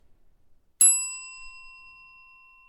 desk bell center
bell bell-hop bellhop desk ding front ring sound effect free sound royalty free Sound Effects